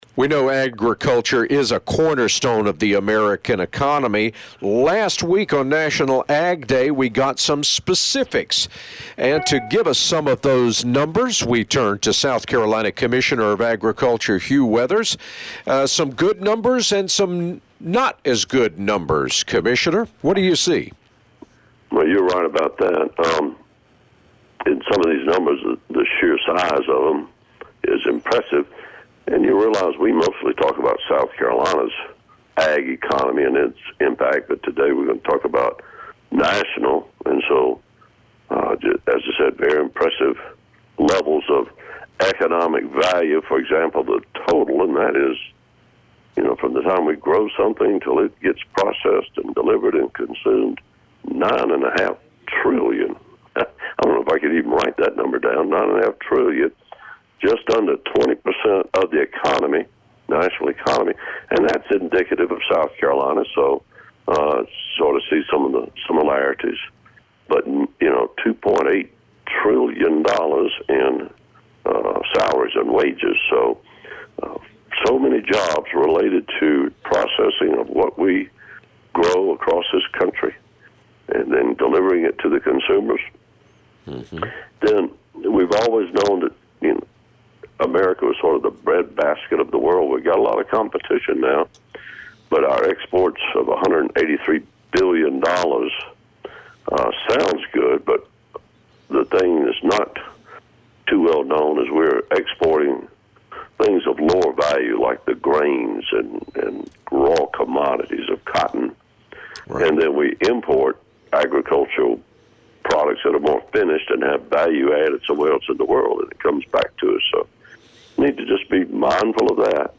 Every week, Commissioner Hugh Weathers discusses the current state of agriculture in South Carolina with The Southern Farm Network. His insights are shared through interviews that cover various topics relevant to the agricultural sector.